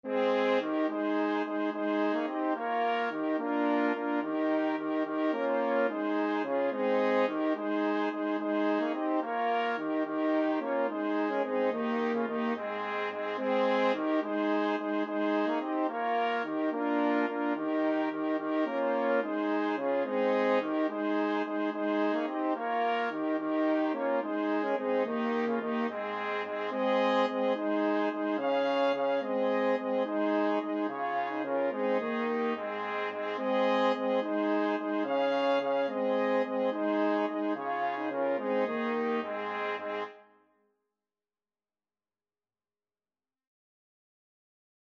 Free Sheet music for Brass Quartet
Trumpet 1Trumpet 2French HornTrombone
6/4 (View more 6/4 Music)
Eb major (Sounding Pitch) (View more Eb major Music for Brass Quartet )
Brass Quartet  (View more Intermediate Brass Quartet Music)
Classical (View more Classical Brass Quartet Music)